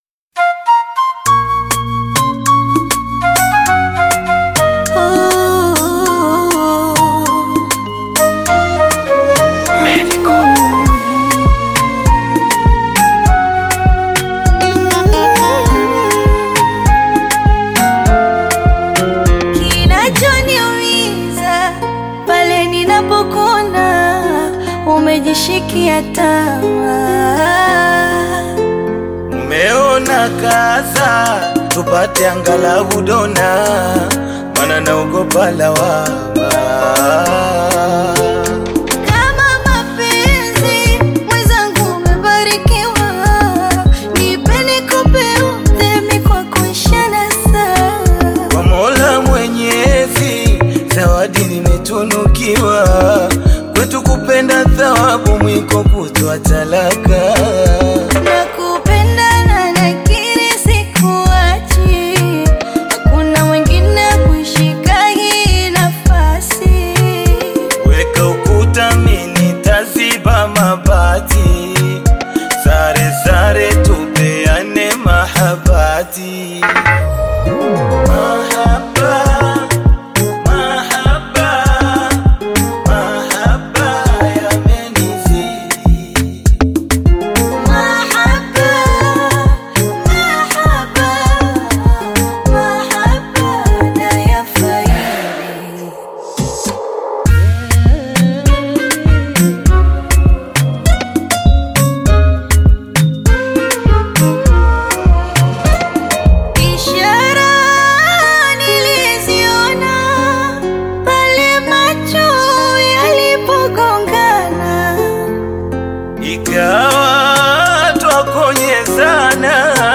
Bongo Flava
soulful vocals
If you’re a fan of Love songs, then this one is a must-have.